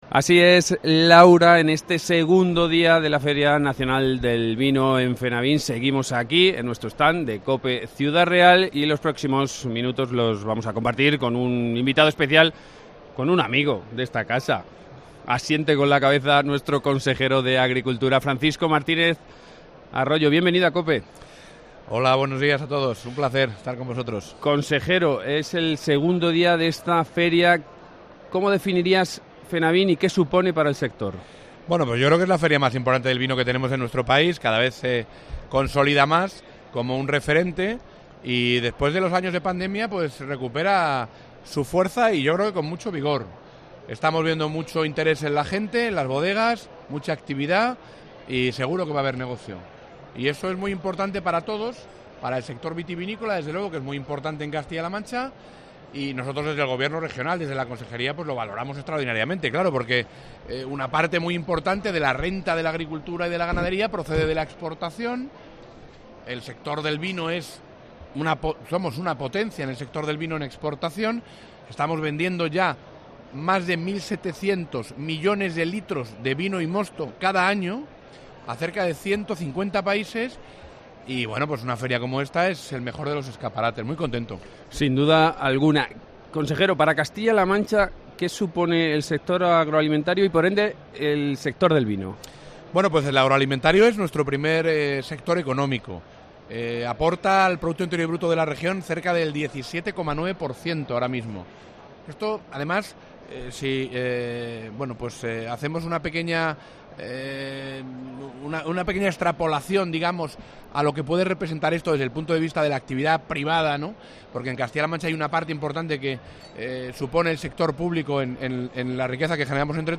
AUDIO: Charlamos con el consejero de Agricultura en el marco de la Feria Nacional del Vino, Fenavin.
Entrevista